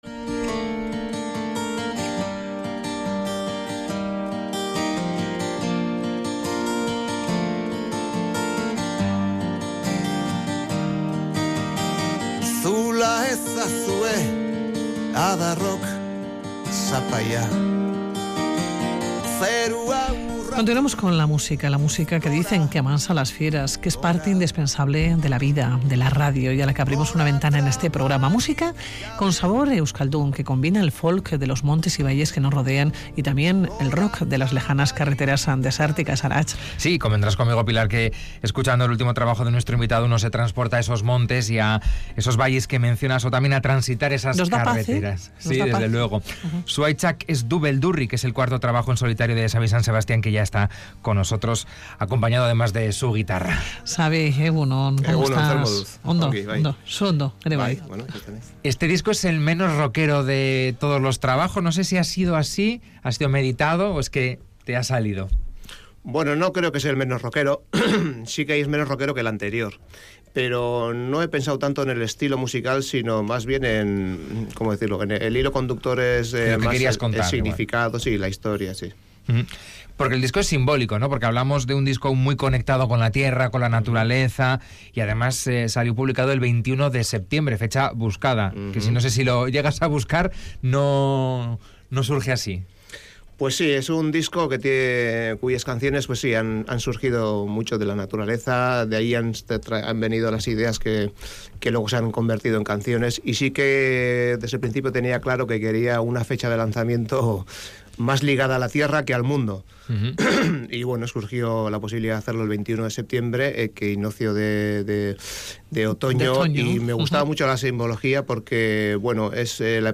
música con sabor euskaldun y que combina el folk de los montes y valles y el rock de las lejanas carreteras.